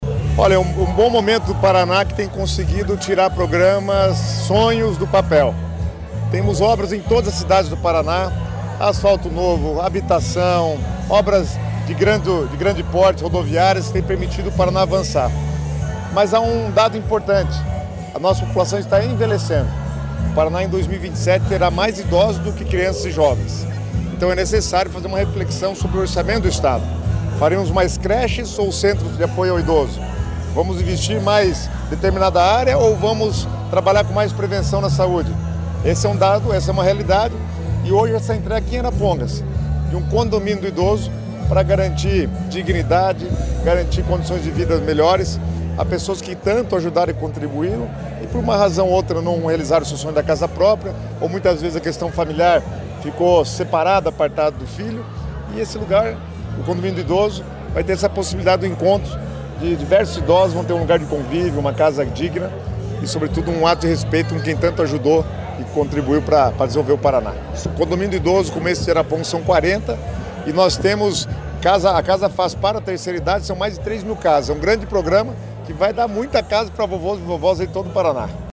Sonora do secretário das Cidades, Guto Silva, sobre a entrega do Condomínio do Idoso de Arapongas